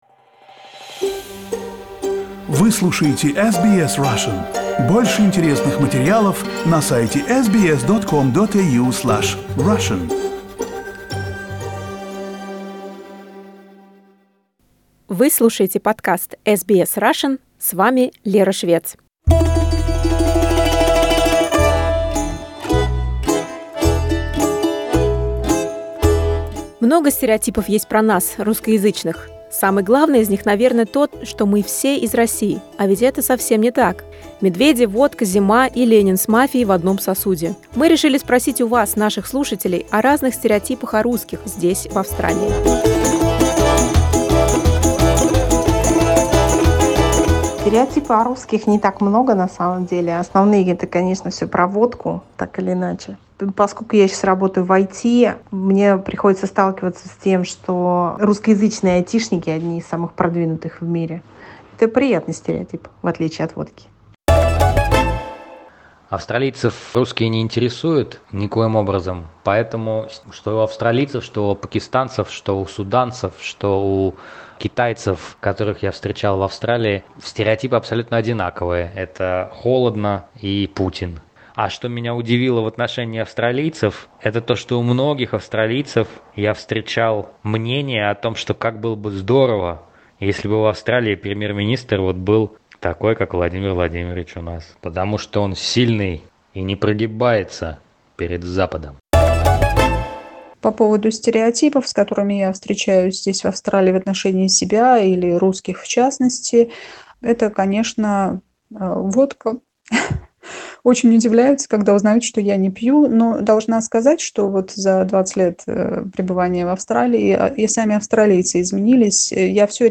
Vox-pop: What are the stereotypes of Russians in Australia?
Some of our listeners shared their thoughts on various stereotypes of Russians they've encountered in Australia.